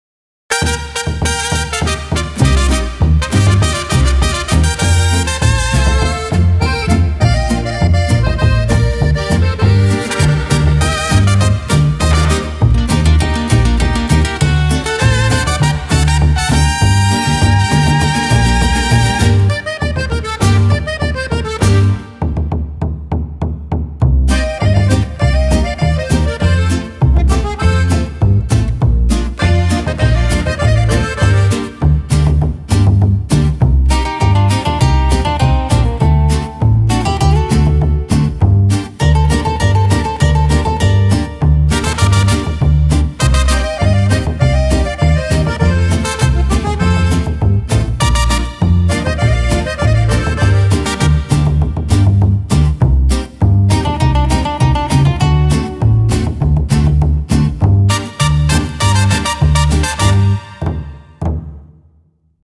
墨西哥及民族鼓
深入了解传统墨西哥及全球打击乐器那深邃悠远、质朴厚重的韵律与质感。
包含乐器： 胡埃胡埃特尔、卡洪鼓、框鼓、特波纳兹特利、乌杜鼓和特科马特。
Huehuetl_Demo.mp3